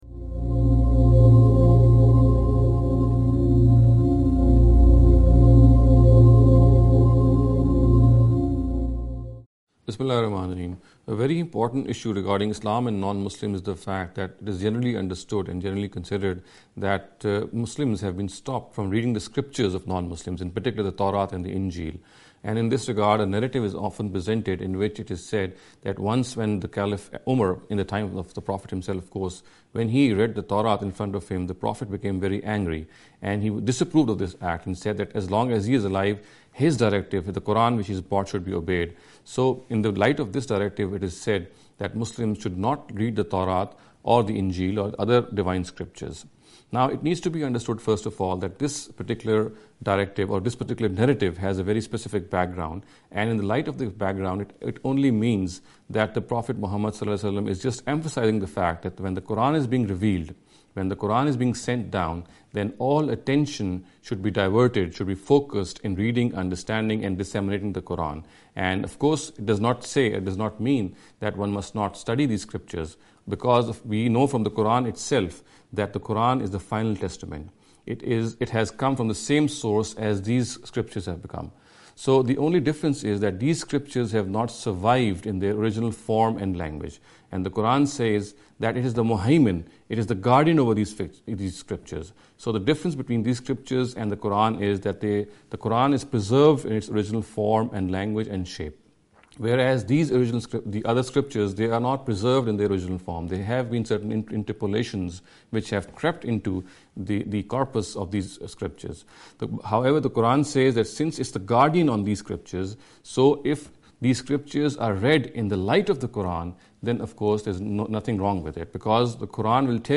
This lecture series will deal with some misconception regarding the Islam and Non-Muslims. In every lecture he will be dealing with a question in a short and very concise manner. This sitting is an attempt to deal with the question 'Reading other Divine Books’.